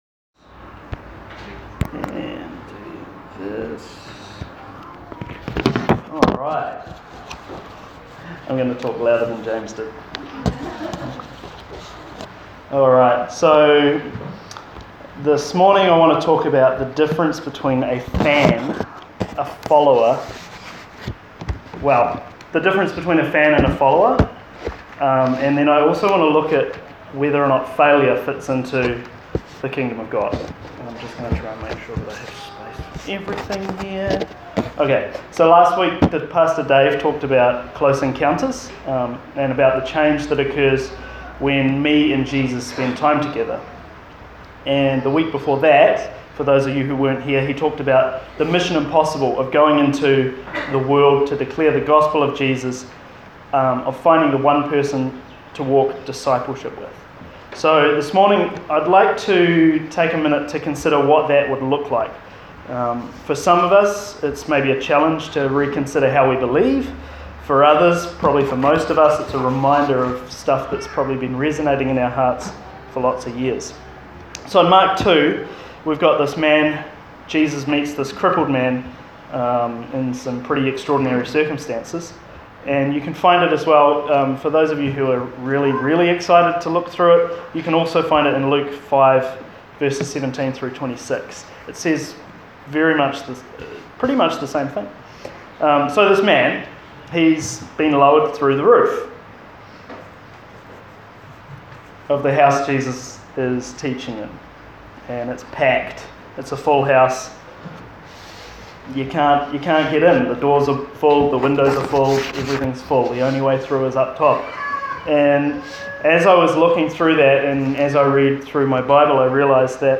voice-preach.m4a